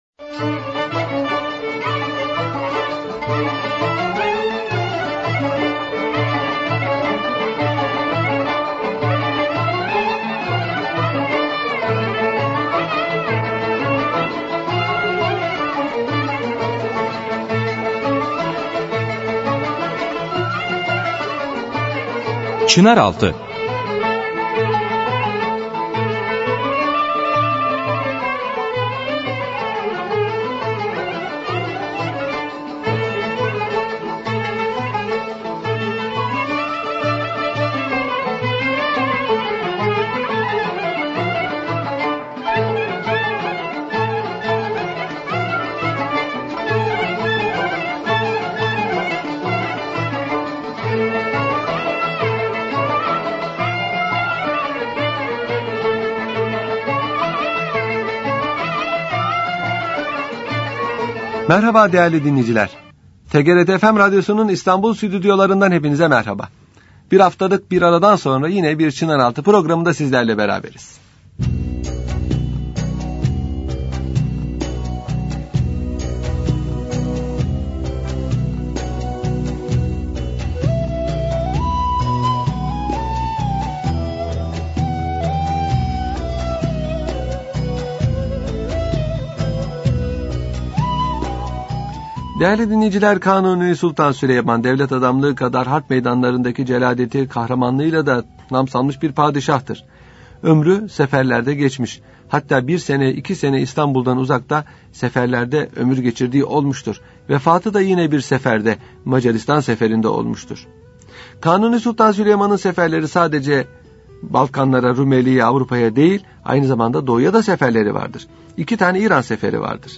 Radyo Programi - İskender Paşa - Erzurum - Türkler ve Müslümanlık 1 - Türkler ve Müslümanlık 2